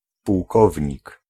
Ääntäminen
US : IPA : /ˈkɝ.nəl/ UK : IPA : /ˈkɜː.nəl/